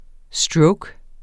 Udtale [ ˈsdɹɔwg ]